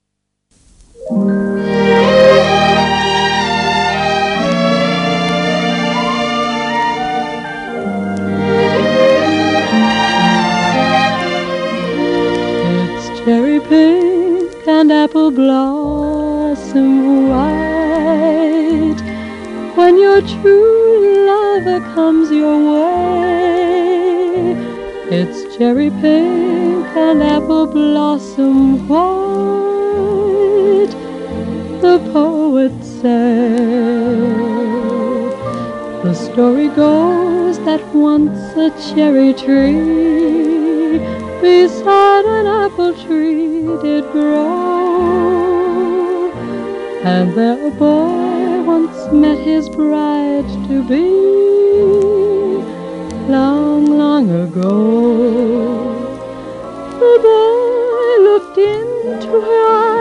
オーケストラ
1950年代の録音。